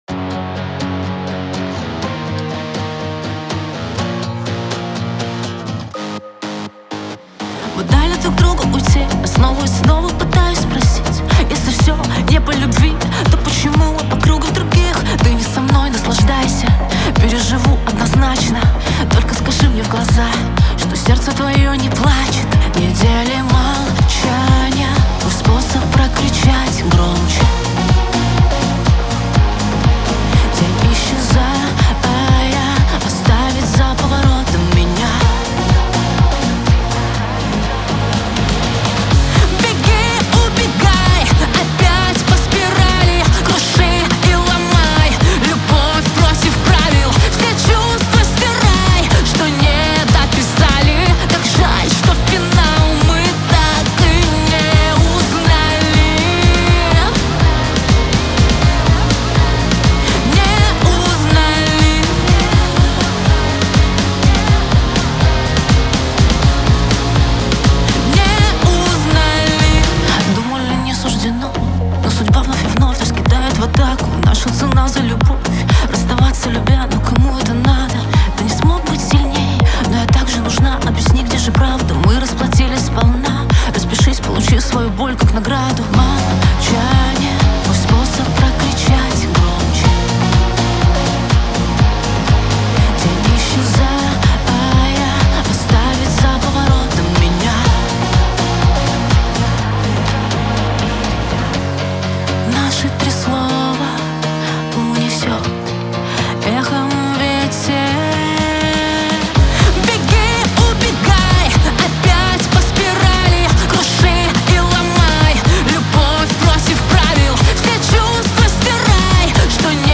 диско , pop